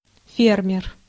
Ääntäminen
Synonyymit agriculteur Ääntäminen France: IPA: [fɛʁ.mje] Haettu sana löytyi näillä lähdekielillä: ranska Käännös Konteksti Ääninäyte Substantiivit 1. фермер {m} (fermer) maatalous 2. арендатор {m} (arendator) Suku: m .